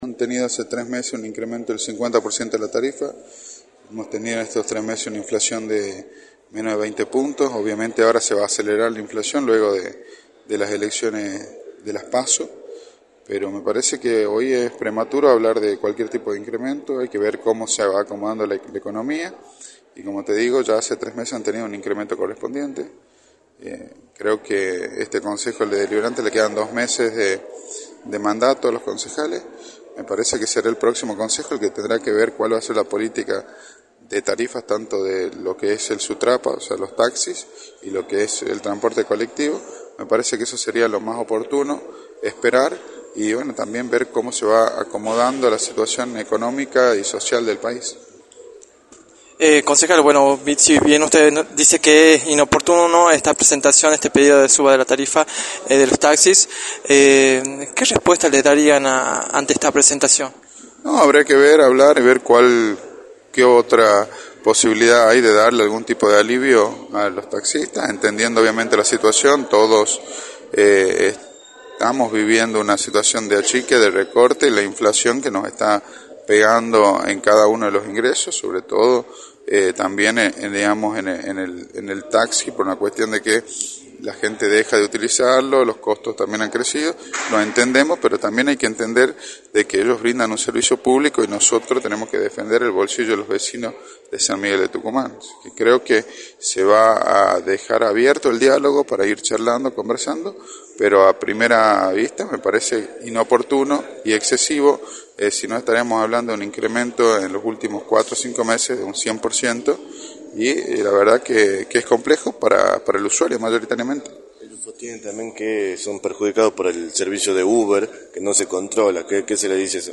Agustín Romano Norri, Concejal de San Miguel de Tucumán, indicó en Radio del Plata Tucumán, por la 93.9, las repercusiones que tuvo en el Concejo Deliberante el pedido de aumento solicitado por los taxistas.